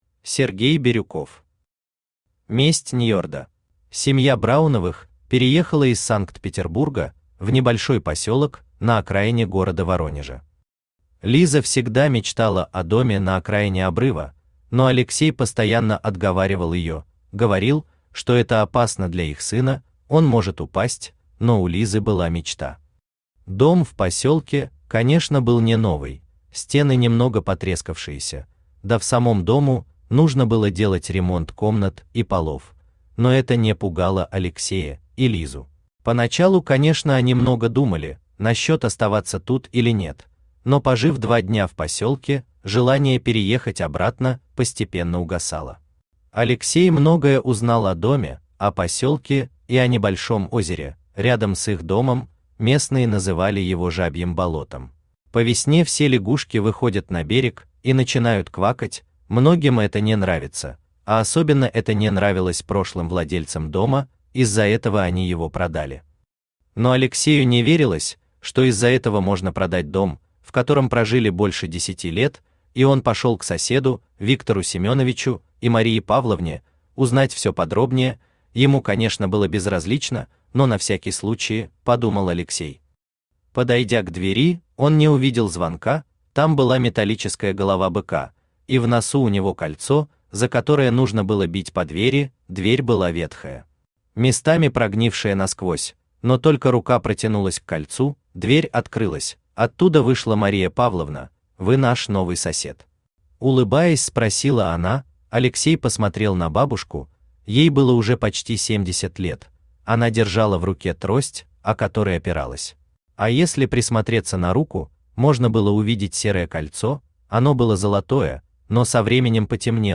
Аудиокнига Месть Ньёрда | Библиотека аудиокниг
Прослушать и бесплатно скачать фрагмент аудиокниги